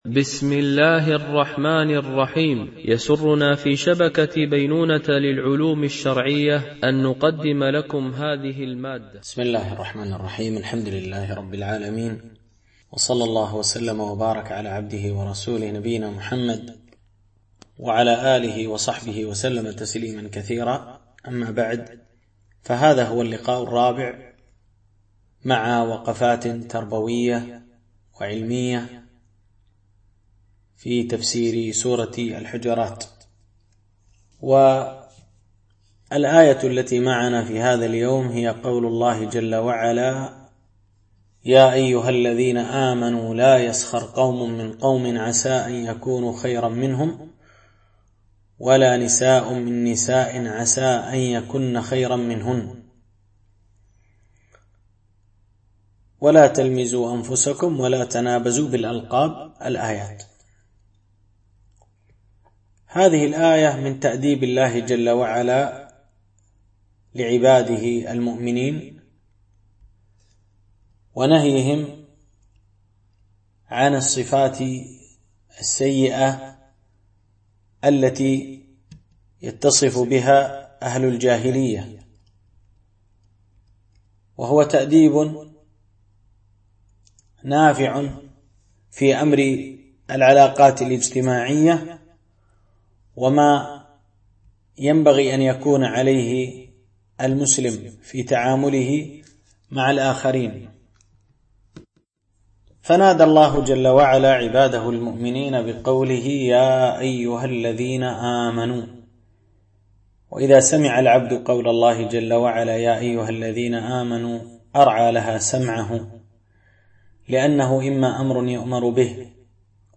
سلسلة محاضرات